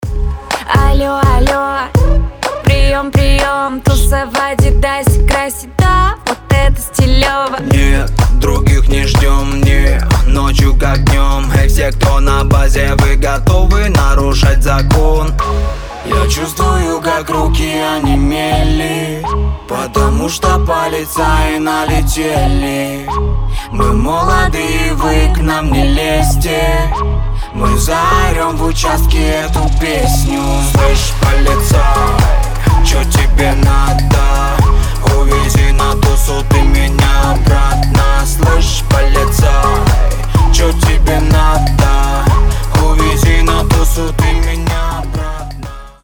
• Качество: 320, Stereo
поп
веселые
заводные
dance
club
клубняк